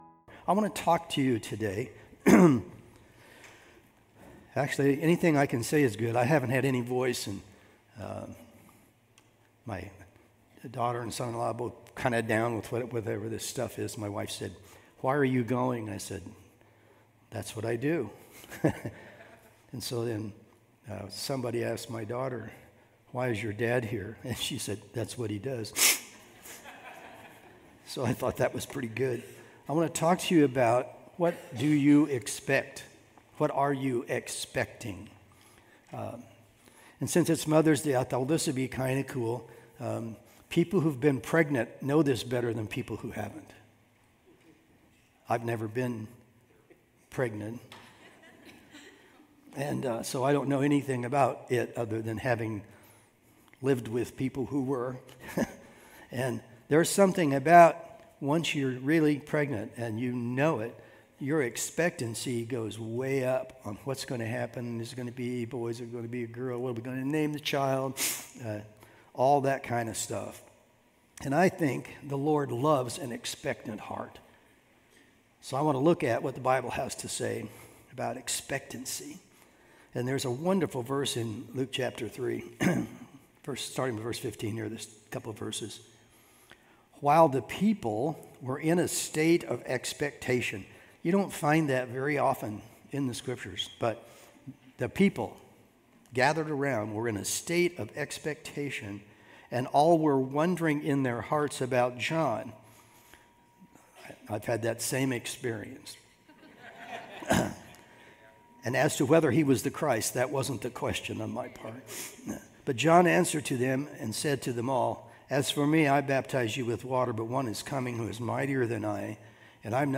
2 Peter 1:12-13 Service Type: Sunday Morning Sermon Download Files Notes